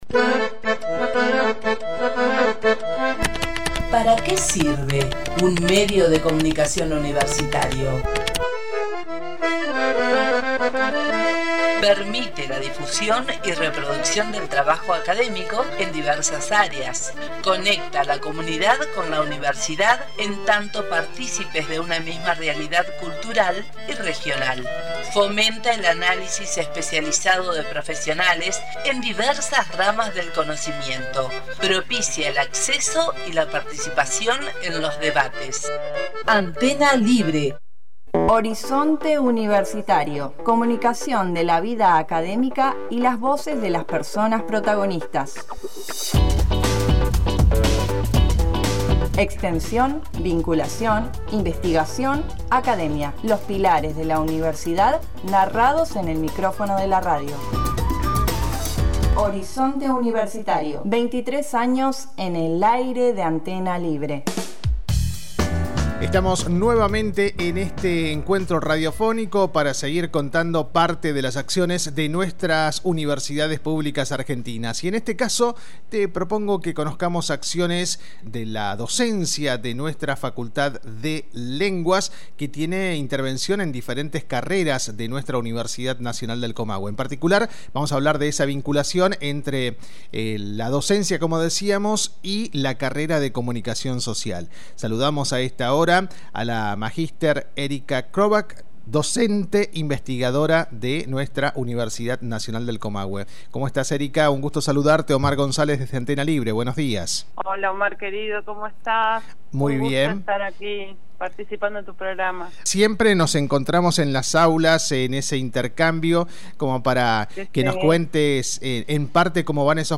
En Horizonte Universitario , hablamos con la docente investigadora